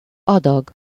Ääntäminen
Synonyymit quartier tranche Ääntäminen Tuntematon aksentti: IPA: /pɔʁ.sjɔ̃/ Haettu sana löytyi näillä lähdekielillä: ranska Käännös Ääninäyte Substantiivit 1. rész Muut/tuntemattomat 2. adag Suku: f .